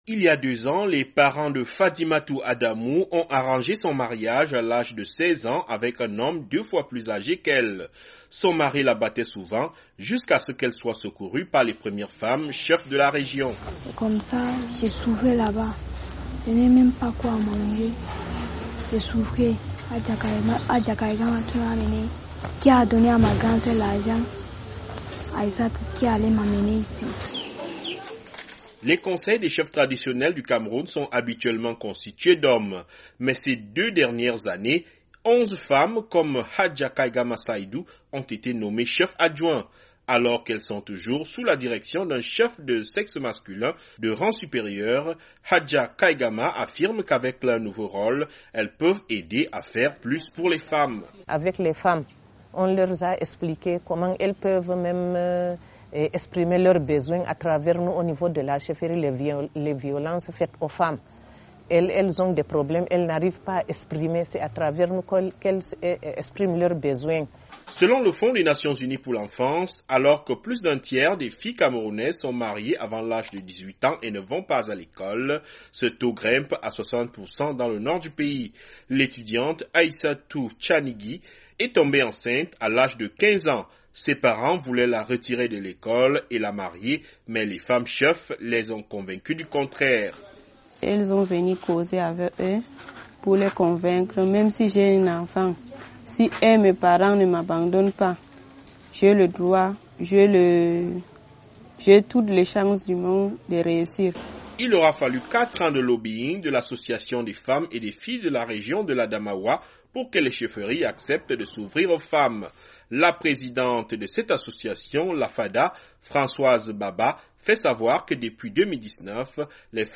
Dans le nord du Cameroun, les conseils des chefs traditionnels étaient habituellement composés uniquement d’hommes, mais au cours des deux dernières années, ils se sont ouverts aux femmes. Certaines sont pionnières dans la lutte contre le mariage précoce, dans une région où la majorité des filles sont mariées avant l'âge de 18 ans. Reportage